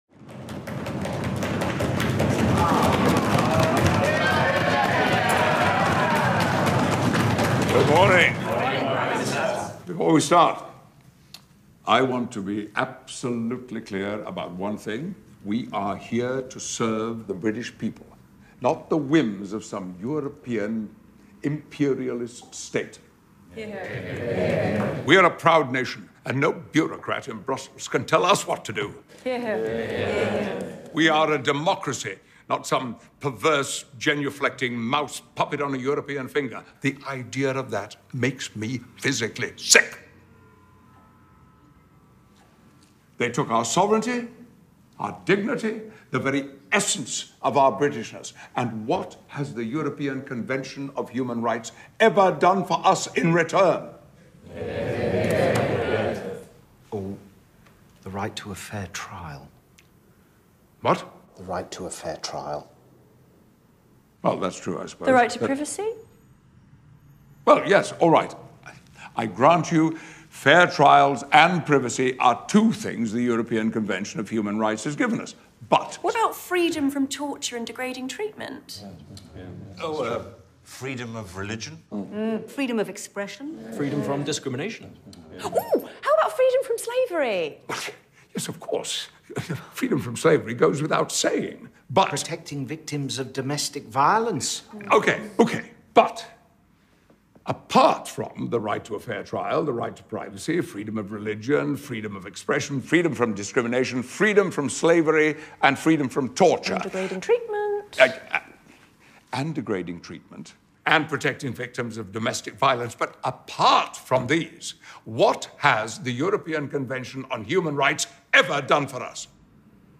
In their take on the classic scene from Monty Python’s movie ‚The Life of Brian’ (1979) where a group of plotting revolutionaries discuss the merits of life under Roman reign while mimicking the dynamics of contemporary ideological discourse, Patrick Stewart, Adrian Scarborough and Sarah Solemani expose the problems in the Conservative plan for a ‘British Bill of Rights’.